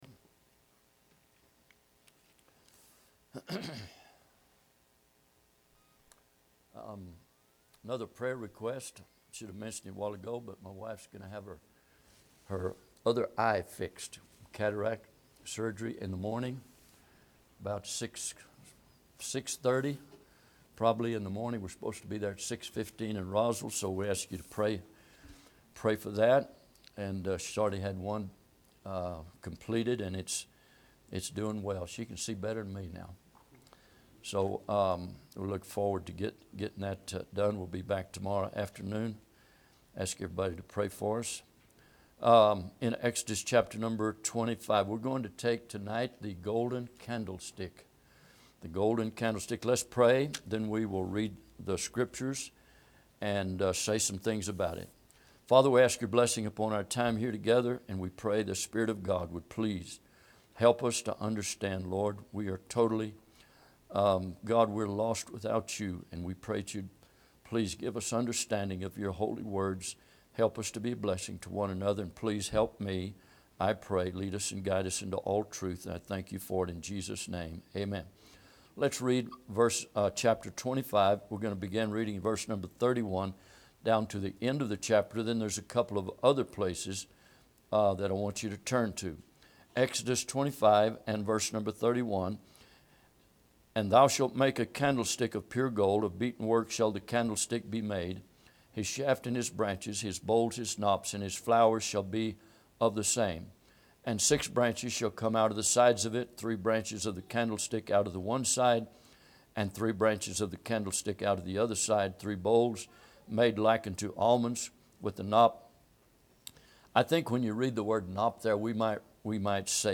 Exodus 25:31-40 Service Type: Midweek Bible Text